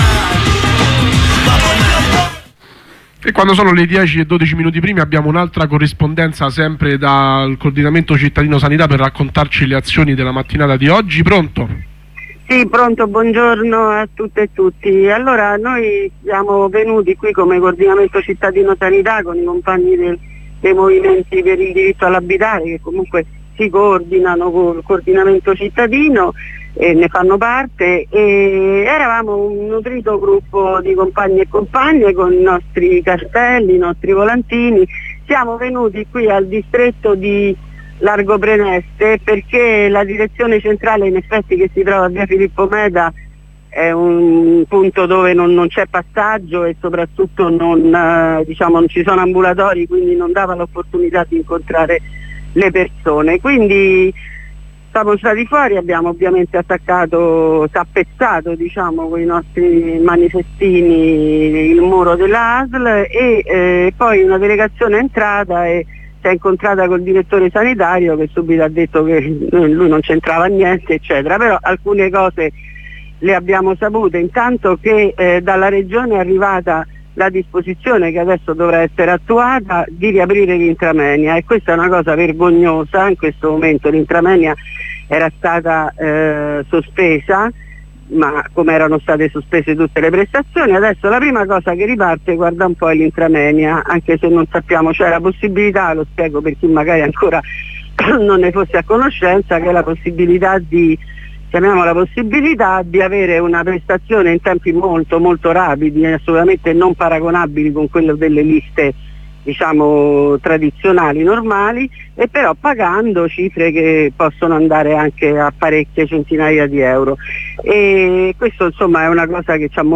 Corrispondenze con due compagne del Coordinamento Cittadino Sanità per raccontare le azioni alle sedi delle ASL territoriali per consegnare la lettera di denuncia sul diritto alla salute nella nostra città ai tempi del Covid-19.